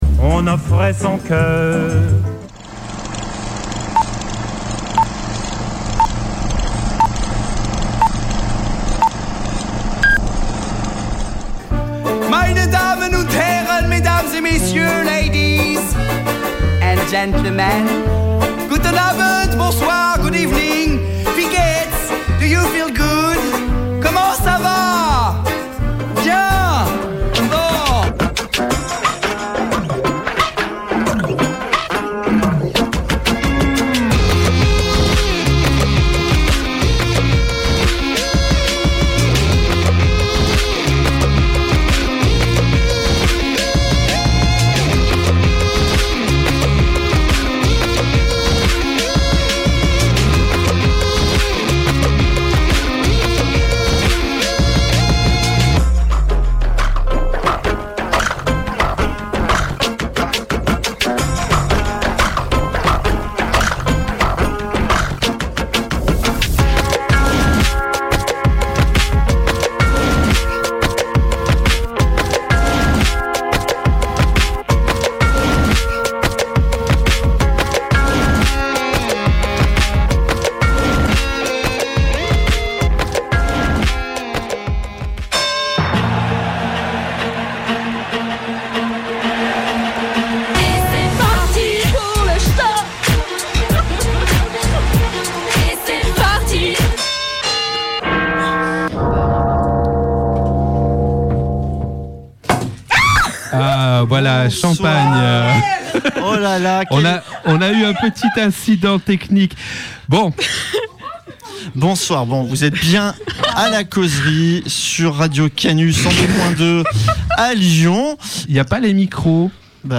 Un format décalé pour cette émission spéciale 100 ème. D’abord la lecture d’une lettre venue du futur puis un jeu quizz entre les animatrices et animateurs de l’émission, autour d’infos insolites mais qui peuvent faire réfléchir pour certaines.